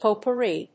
音節pot・pour・ri 発音記号・読み方
/pòʊpʊríː(米国英語), pəʊpˈʊri(英国英語)/